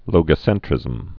(lōgə-sĕntrĭzəm)